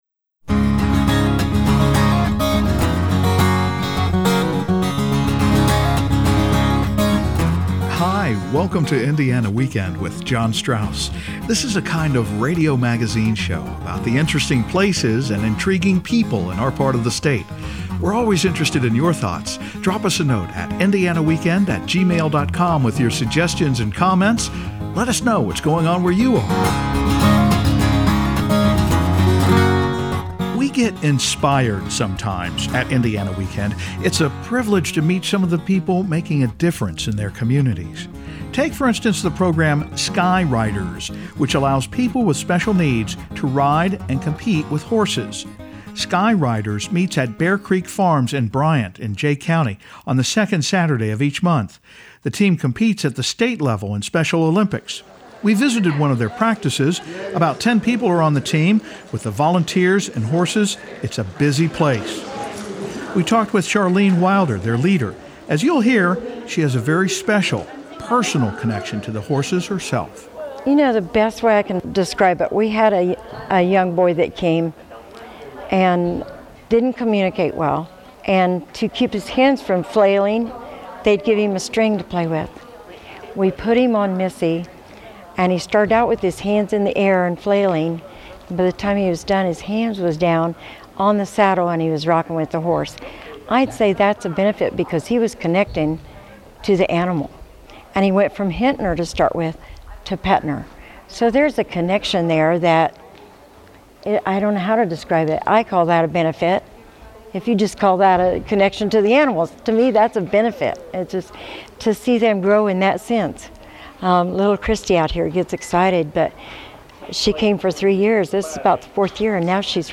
The SkyRiders equestrian team prepares for state competition, and we meet the inspiring competitors and their volunteer coaches at a farm in Jay County.